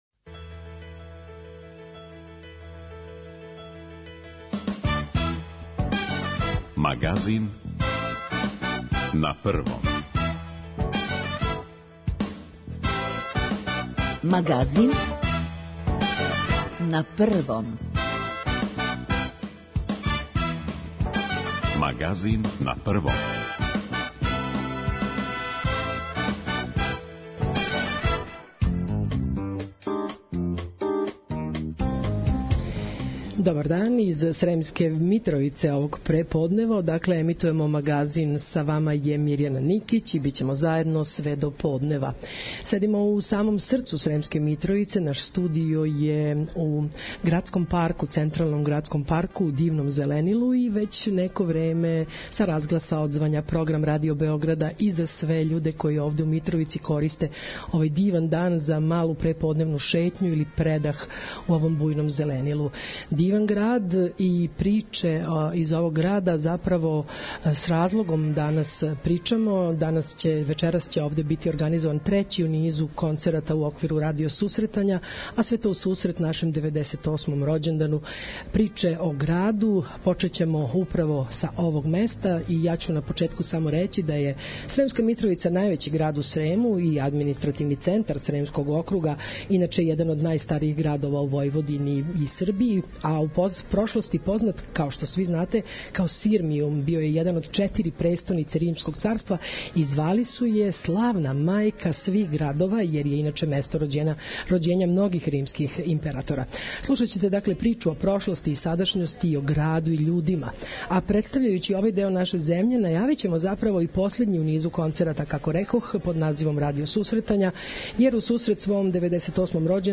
Данашњи Магазин емитујемо из Сремске Митровице, највећег града у Срему, административног центра Сремског округа и једног од најстаријих градова у Војводини и Србији.